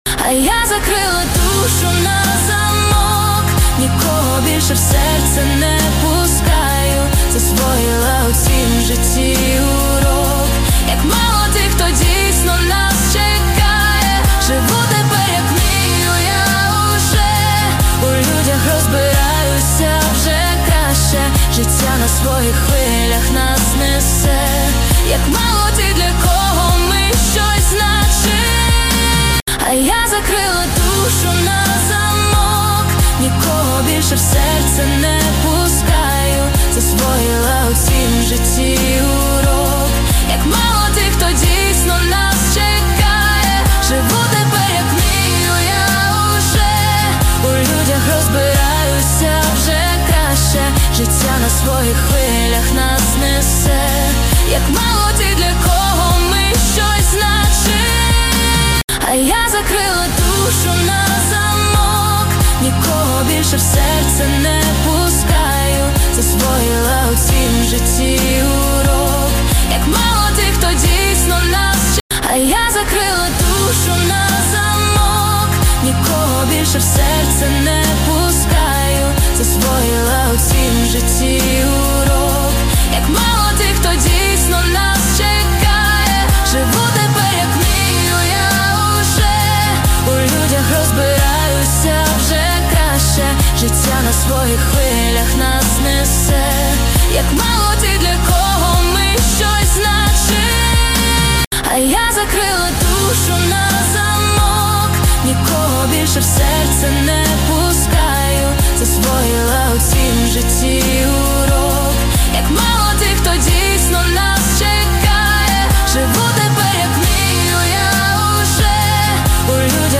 Качество: 320 kbps, stereo